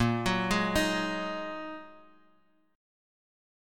A#7sus4#5 chord